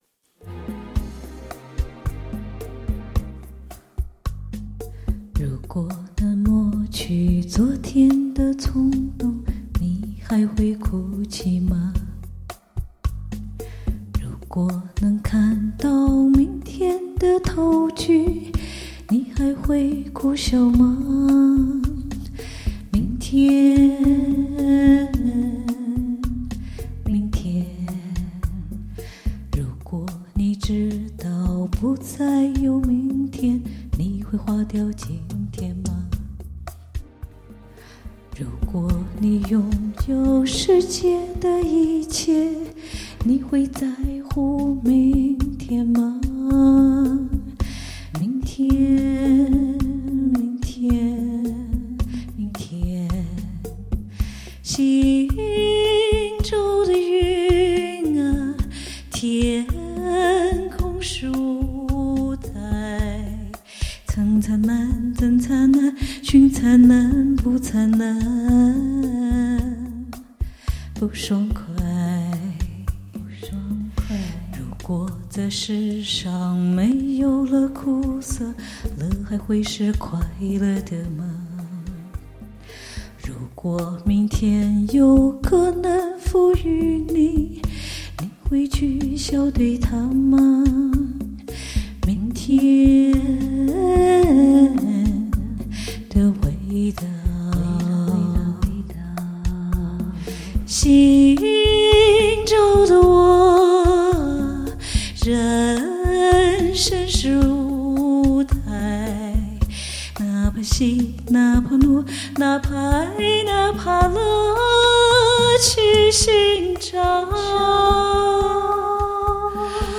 十年山庆暖场 （乱唱）